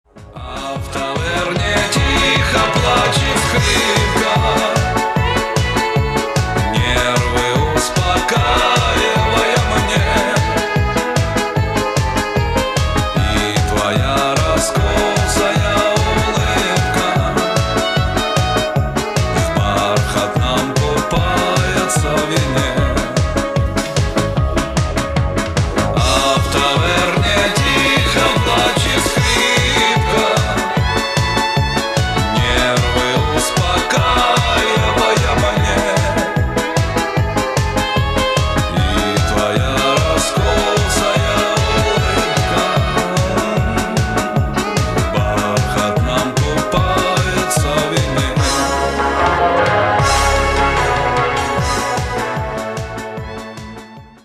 • Качество: 192, Stereo
мужской вокал
спокойные
русский шансон